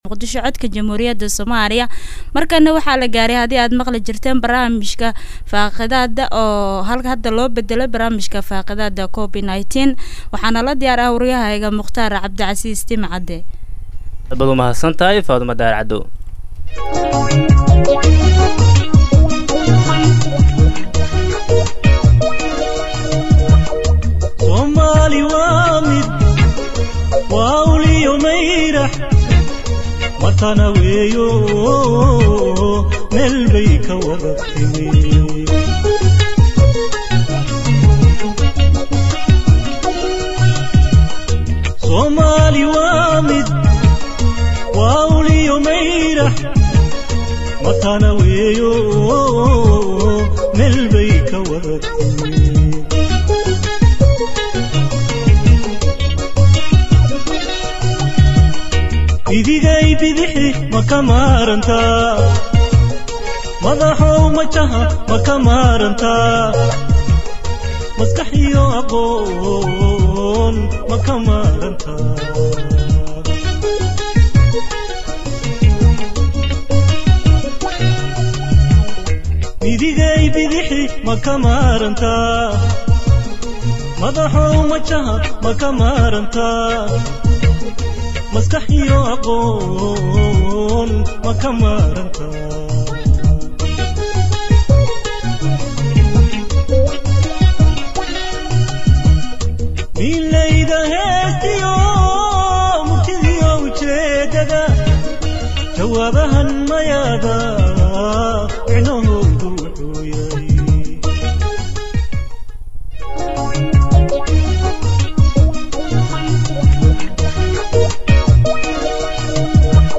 Dhageystayaasheenna sharafta leh ku soo dhawaada barnaamijka Faaqidaadda COVID-19 ee Radio Muqdisho oo maanta aan ku eegeyno xaaladihii ugu dambeeyay ee cudurka adduunka dhibaatada ba’an ku haaya ee COVID-19. Barnaamijkaan waxaa aragtiyadooda ka dhiibtay qaar kamid ah shacabka ku nool magaalada Muqdisho sida ay u arkaan cudurka Coronovirus.